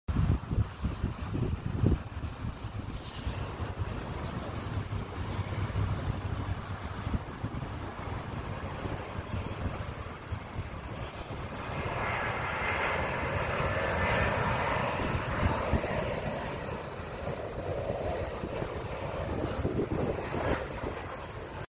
Avião ✈ pousando na praia sound effects free download